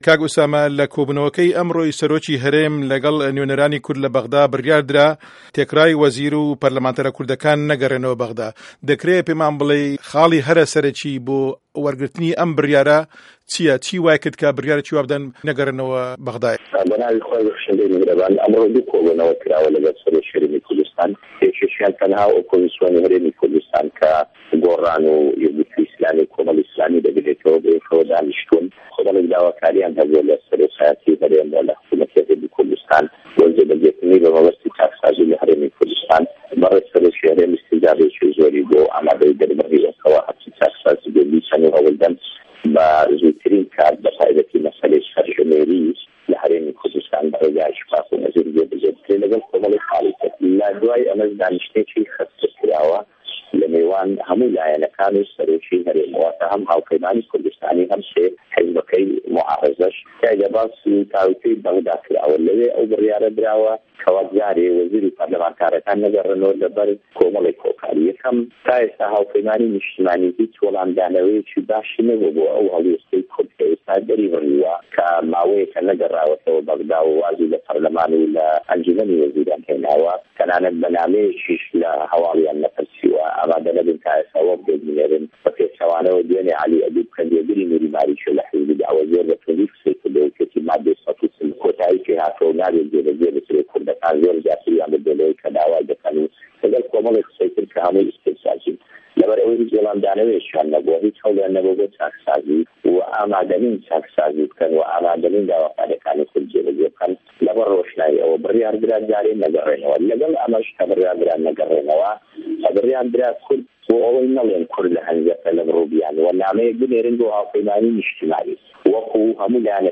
ئوسامه‌ جه‌میل ئه‌ندامی ئه‌نجومه‌نی نوێنه‌رانی عێراق
وتووێژی ئوسامه‌ جه‌میل